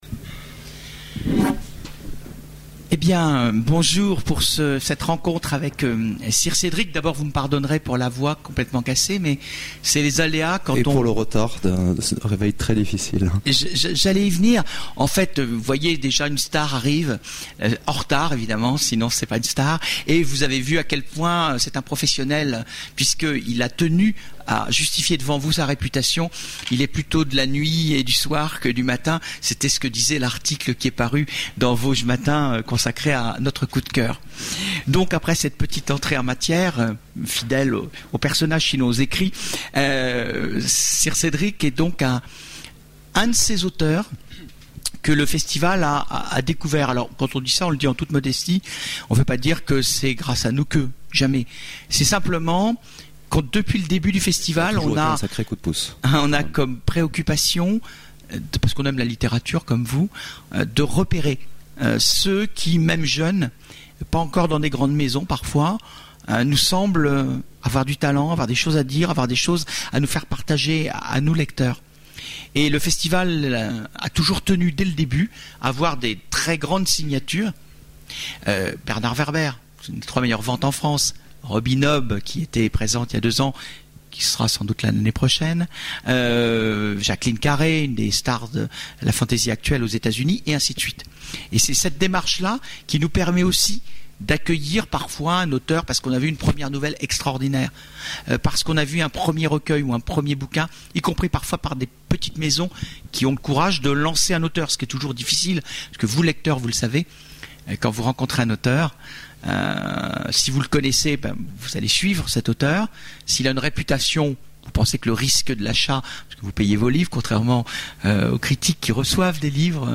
Conférence Imaginales 2010
Conférence
Rencontre avec un auteur